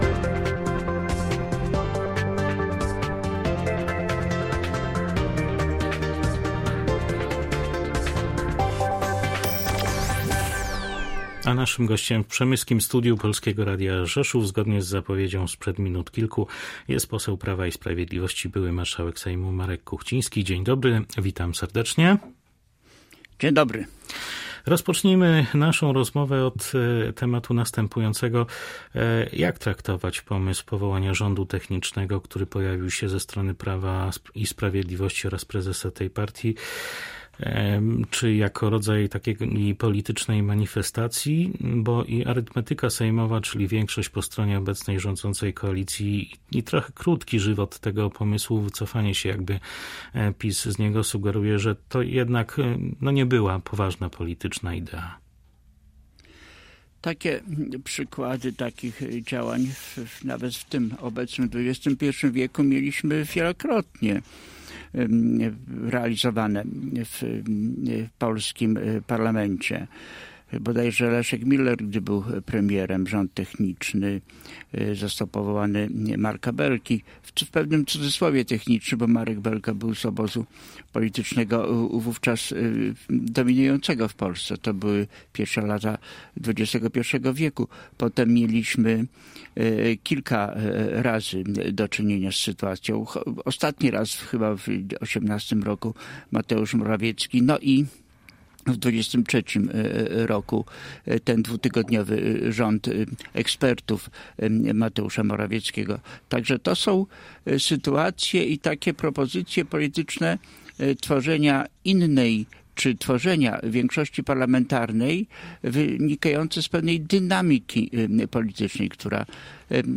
– powiedział na naszej antenie Marek Kuchciński, podkarpacki poseł PiS, były marszałek Sejmu.